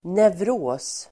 Ladda ner uttalet
Uttal: [nevr'å:s]